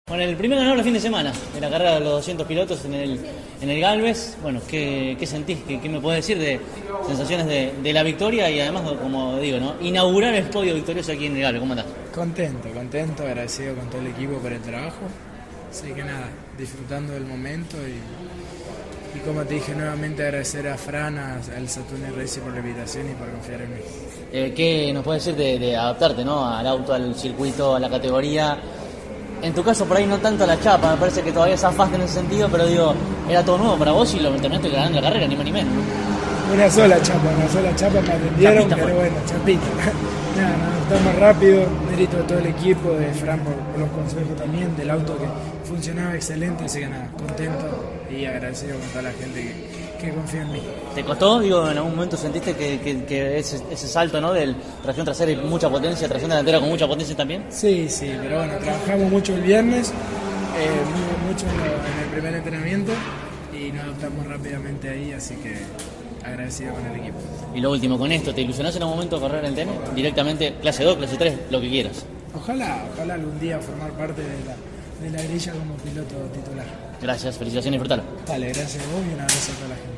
CÓRDOBA COMPETICIÓN estuvo presente en la fecha realizada en el «Autódromo Oscar y Juan Gálvez» y dialogó con los protagonistas de esa primera competencia de la cita porteña.